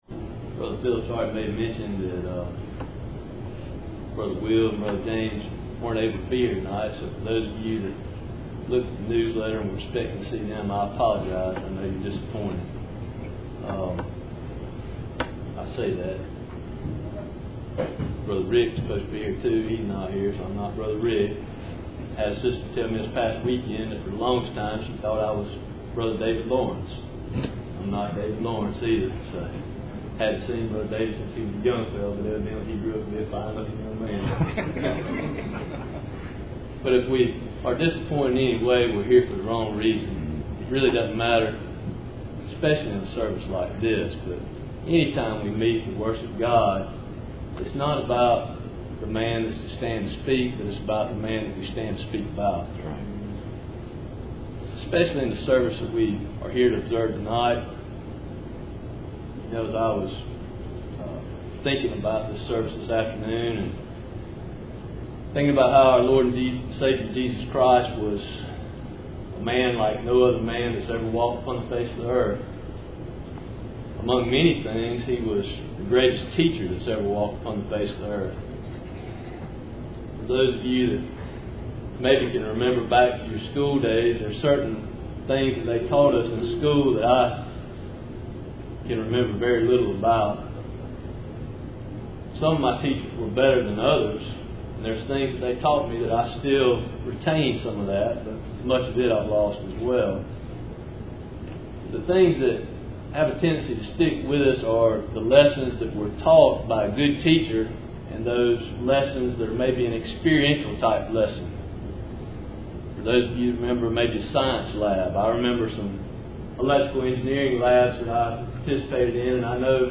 Communion Service August 2017